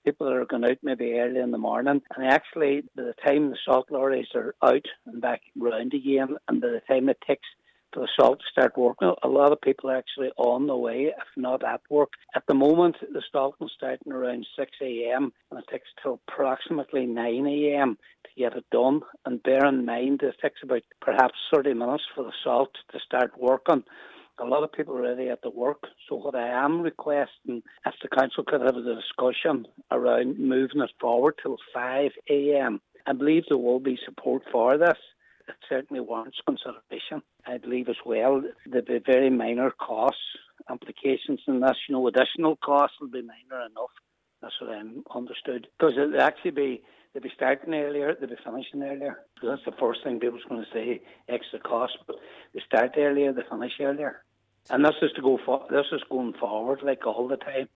Gritters were out on the roads yesterday at 5am but Councillor McGowan believes this needs to be the case going forward: